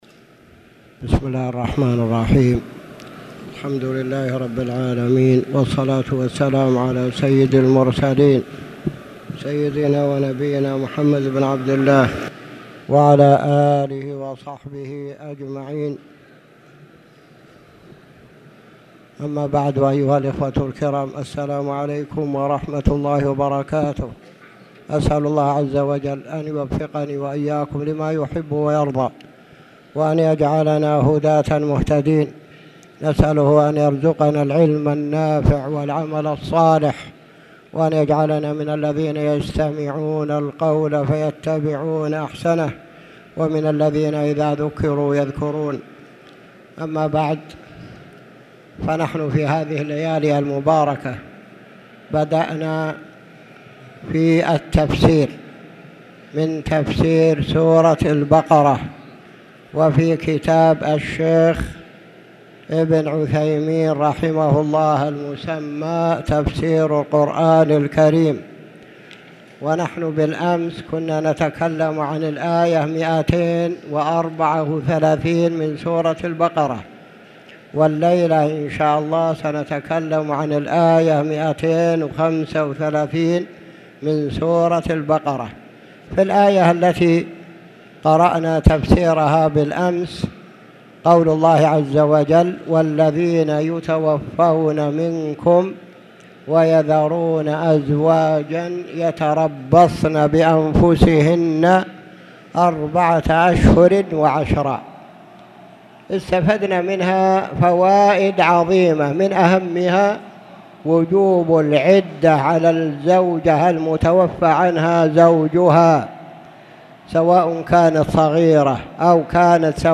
تاريخ النشر ٢٤ ذو الحجة ١٤٣٧ هـ المكان: المسجد الحرام الشيخ